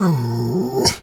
pgs/Assets/Audio/Animal_Impersonations/dog_2_growl_07.wav at master
dog_2_growl_07.wav